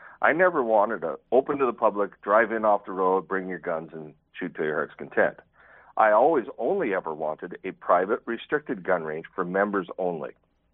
In an interview with 88.1 myFM